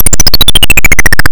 Now we reveal the play area one row of tiles at a time from the bottom up, with accompanying sound effects.
64493 XOR 16 Make a sound effect with pitch and duration determined by the row of the screen that is being revealed